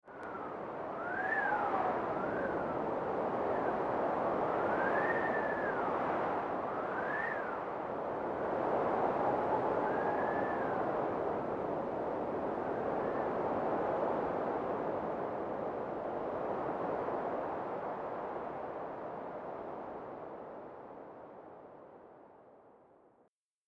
دانلود آهنگ باد 11 از افکت صوتی طبیعت و محیط
جلوه های صوتی
دانلود صدای باد 11 از ساعد نیوز با لینک مستقیم و کیفیت بالا